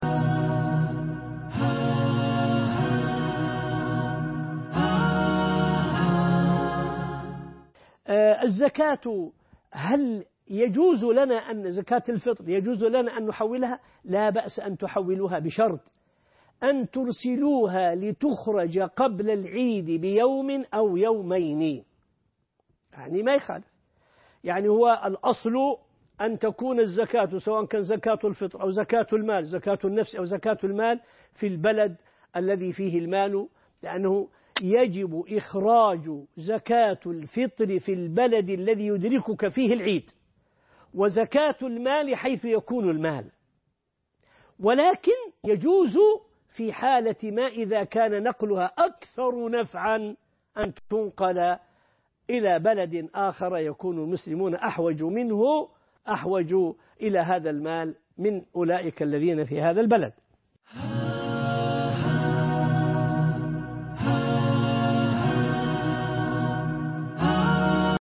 عنوان المادة هل يجوز أن نحول زكاة الفطر أو زكاة المال إلى بلد آخر؟ ( 16/2/2015)سؤال وجواب